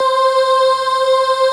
VOICEPAD12-LR.wav